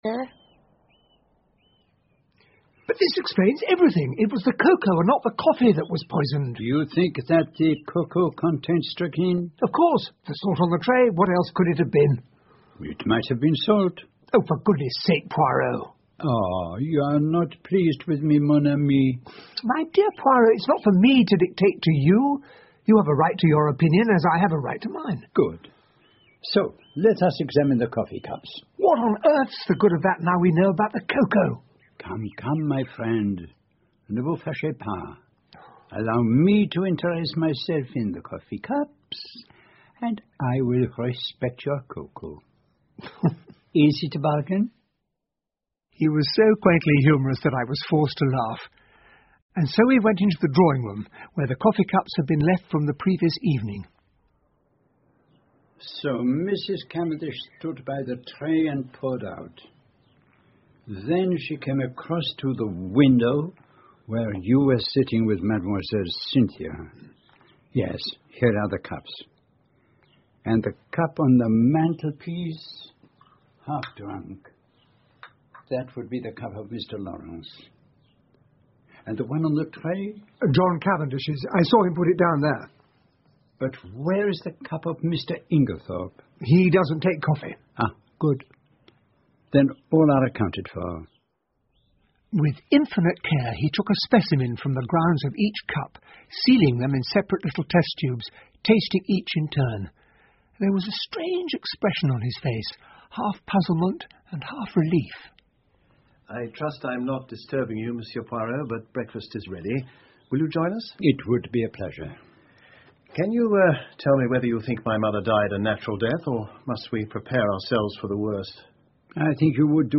英文广播剧在线听 Agatha Christie - Mysterious Affair at Styles 10 听力文件下载—在线英语听力室
在线英语听力室英文广播剧在线听 Agatha Christie - Mysterious Affair at Styles 10的听力文件下载,英语有声读物,英文广播剧-在线英语听力室